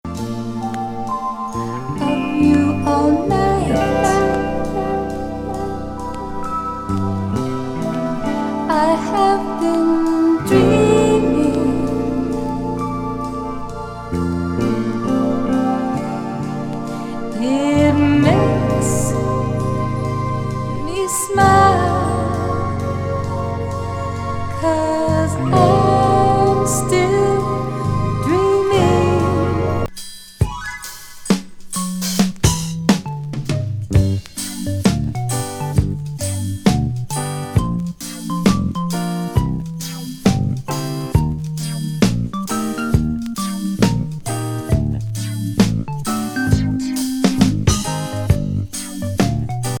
鍵盤をメインに演奏した80年ソロ。シンセ・スムース
ジャズ・ファンク